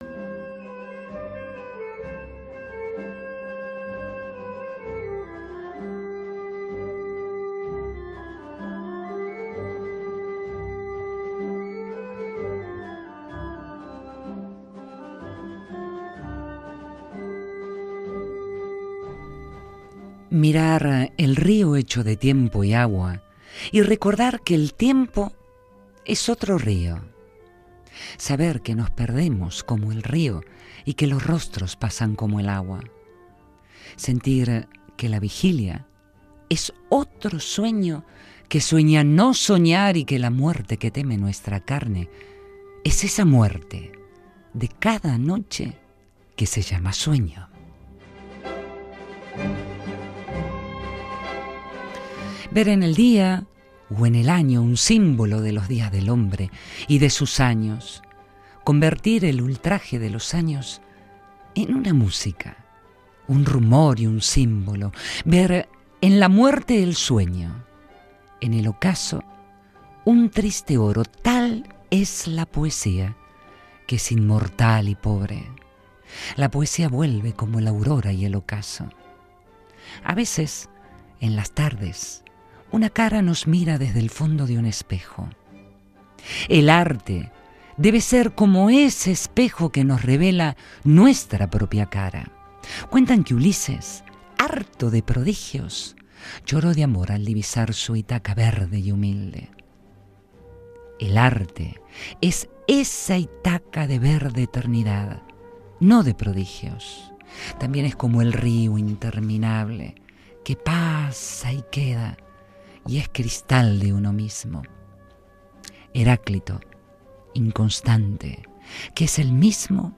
Rapsodeando ''Arte poética'' de Jorge Luis Borges